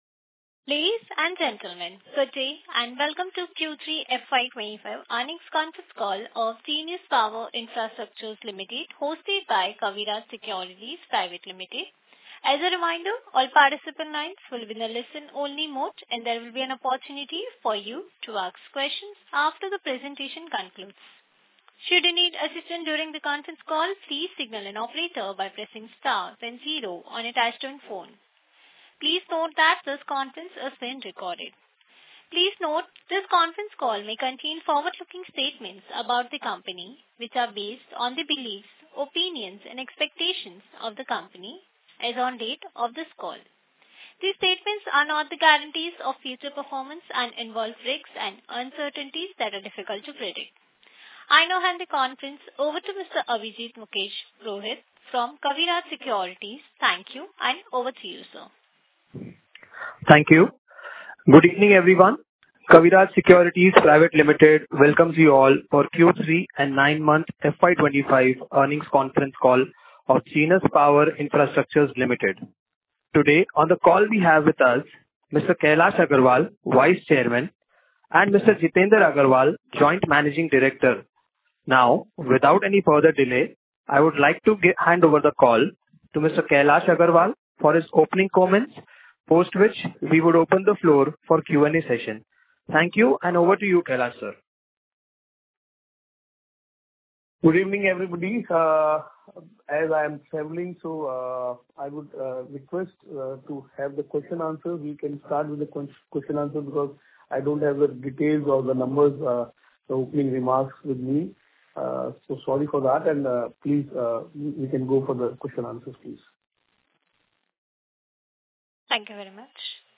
Concalls